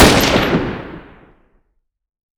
SFX_Assault Single Shot.wav